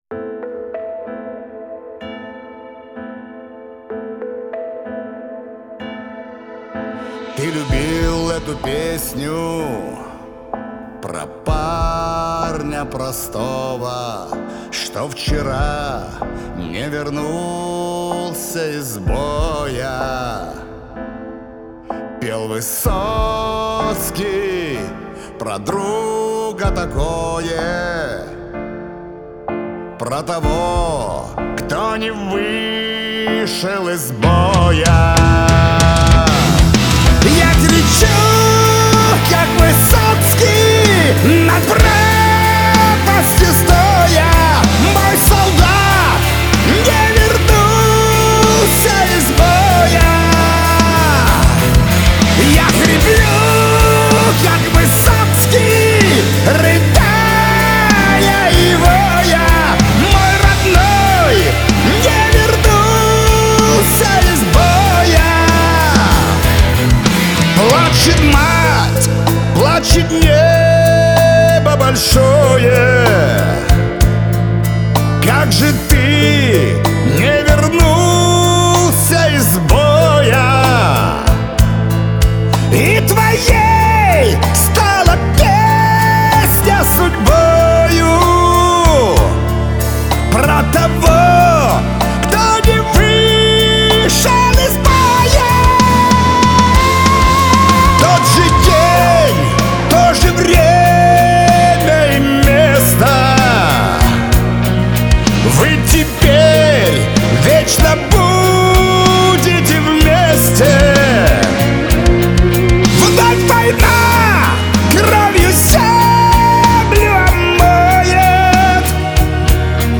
Жанр: Казахские